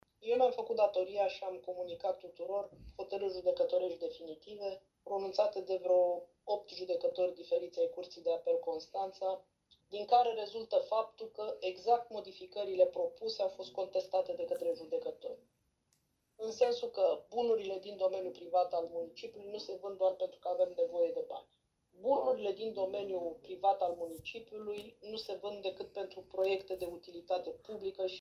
Ședință CLM.